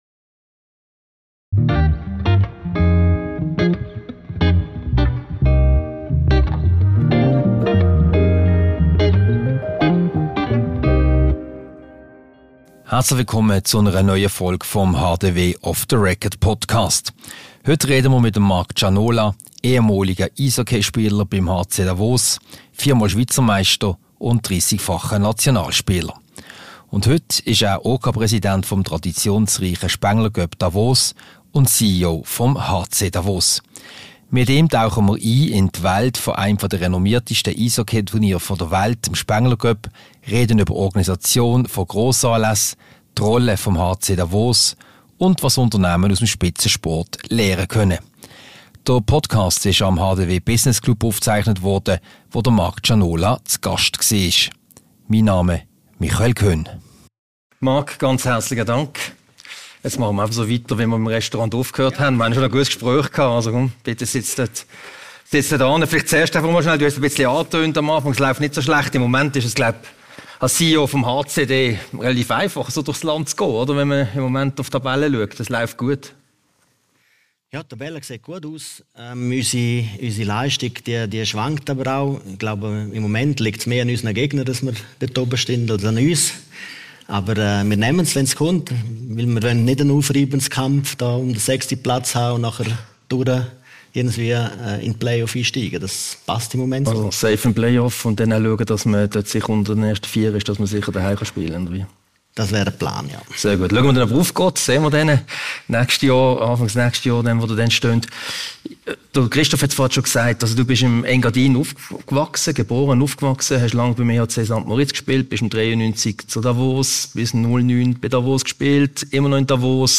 Diese Podcast-Ausgabe wurde anlässlich des HDW Business Club Dinners vom 23. Oktober im Haus der Wirtschaft HDW aufgezeichnet.